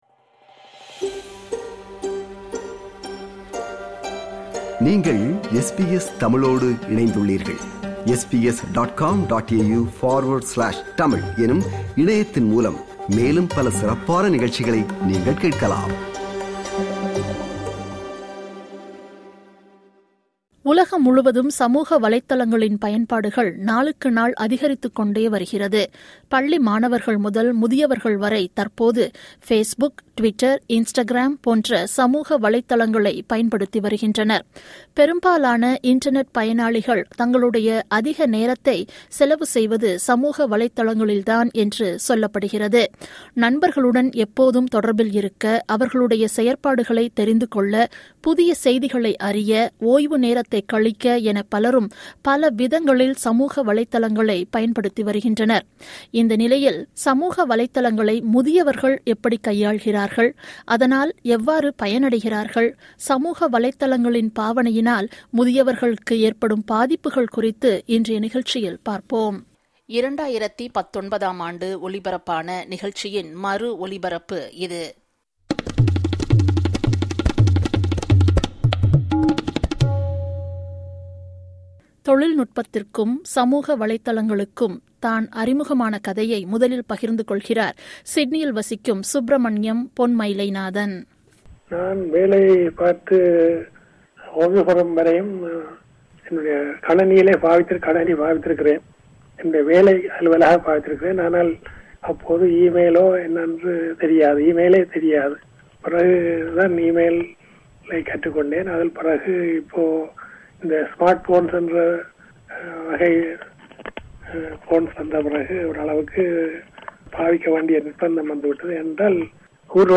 பொழுது போக்காக பாவிக்க ஆரம்பித்த சமூக வலைத்தளங்கள் சில தற்போது நம்மை ஆக்ரமித்து வளர்ந்து நிற்கின்றன. வளர்ந்து வரும் சமூக வலைத்தளங்களின் பயன்பாடு முதியவர்கள் மீது செலுத்தும் ஆதிக்கம் மற்றும் அதில் உள்ள சாதகங்கள், பாதகங்களை விவரிக்கும் விவரணம்.